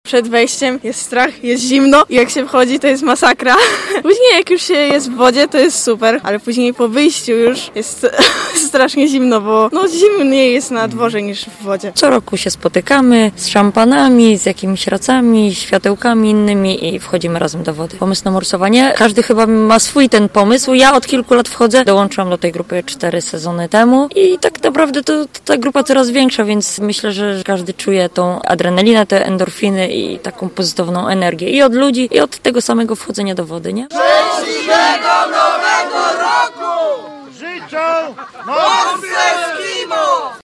Ponad trzydziestu członków gorzowskiego Klubu Morsów „Eskimo”, przywitało nowy rok w jeziorze w Kłodawie.